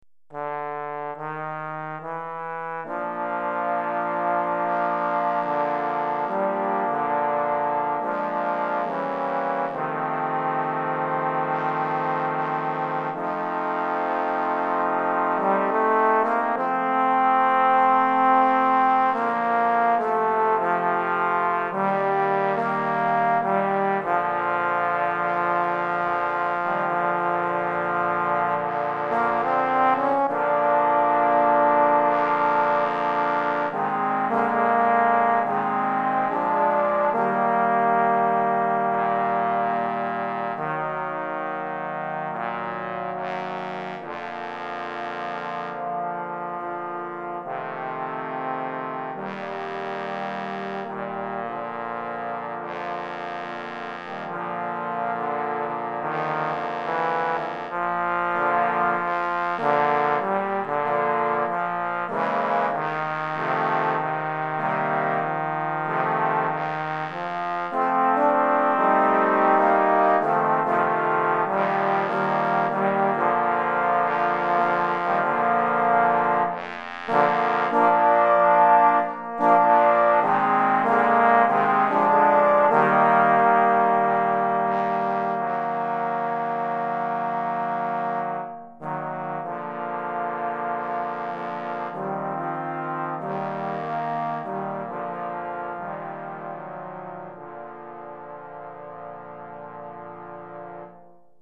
5 Trombones